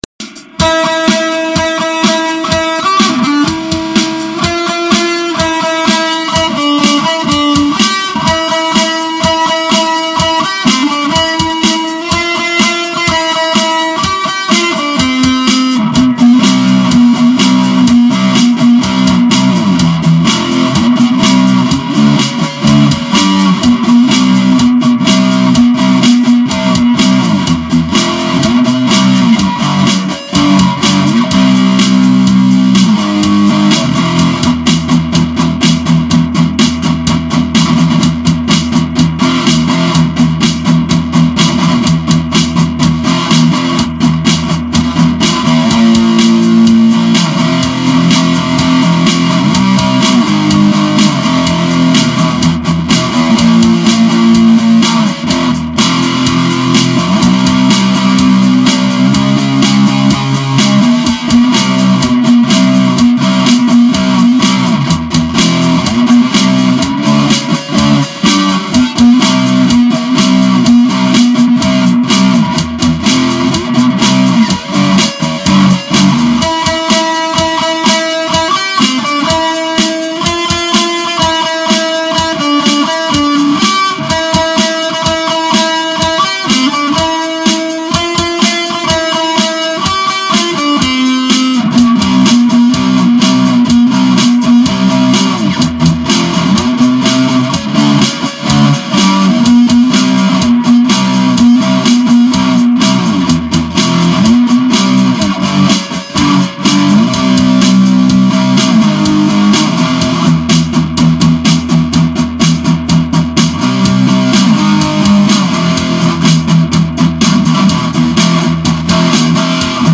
в обработке на гитаре запись со смарта 5530хм(авторская)